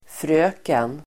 Uttal: [fr'ö:ken]